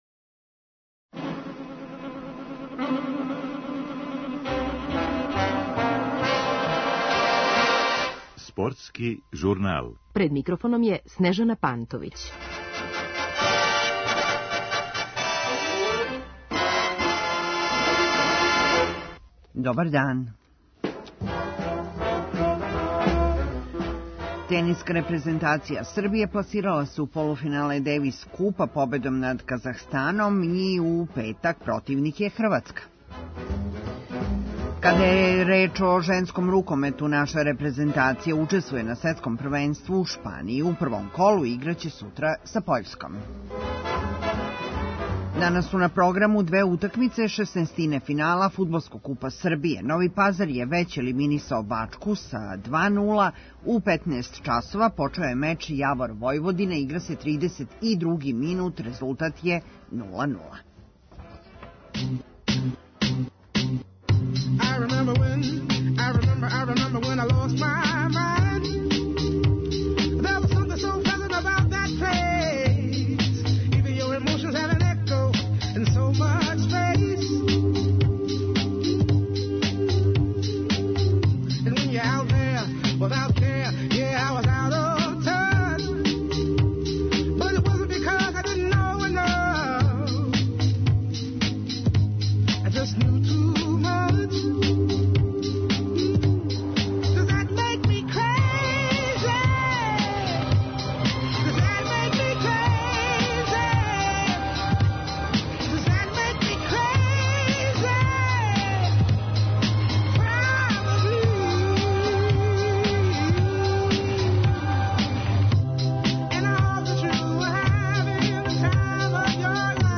Гост стручни коментатор